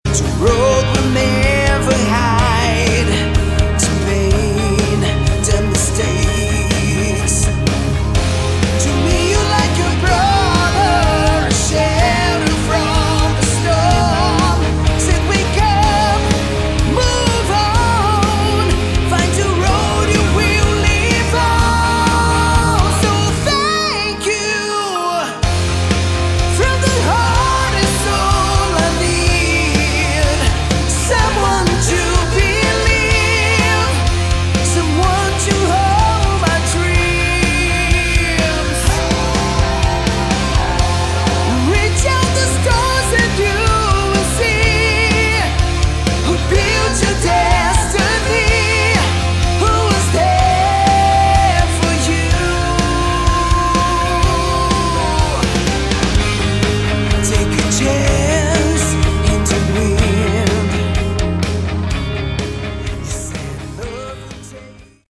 Category: Melodic Rock
vocals
guitars
bass, keyboards, backing vocals
drums